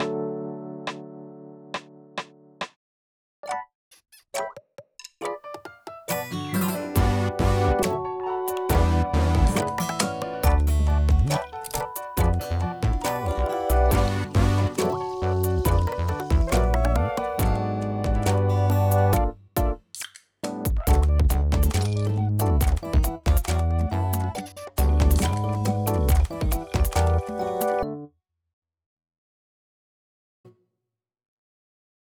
inst（カラオケ）素材など
音源（BPMは138）
いたって普通の女性ボーカルくらいのキーだと思います。